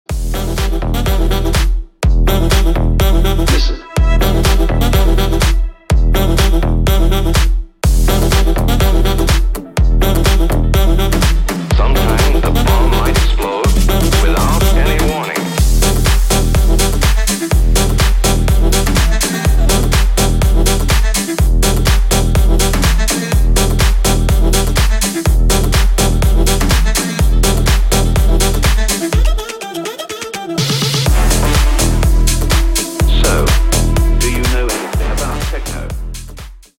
ритмичные
веселые
EDM
Саксофон
Tech House